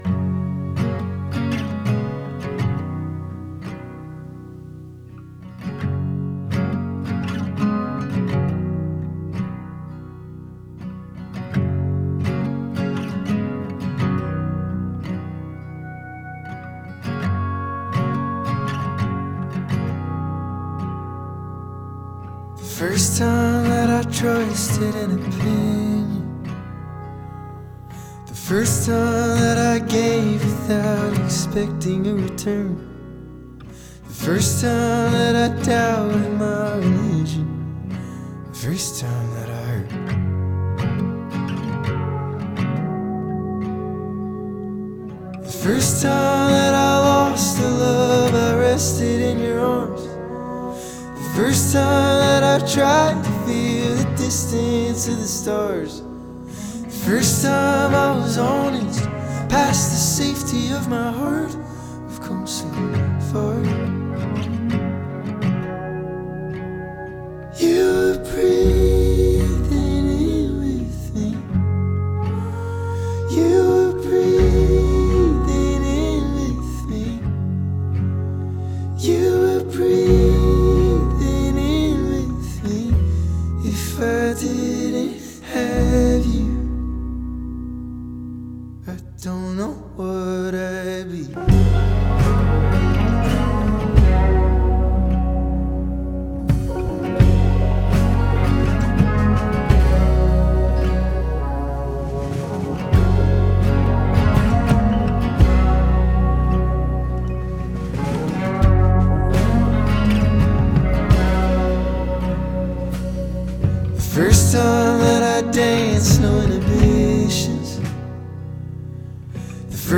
una guitarra, una voz, unos versos emotivos y nada más